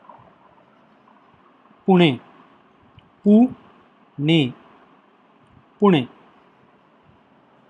Pune (Marathi: Puṇē, pronounced [ˈpuɳe]
Pune_pronunication.ogg.mp3